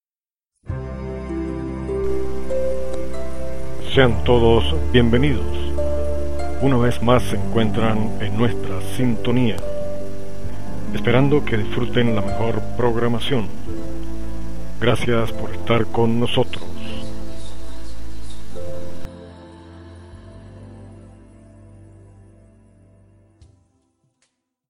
Locutor de Radio y Television en comerciales, doblajes, eventos
Kein Dialekt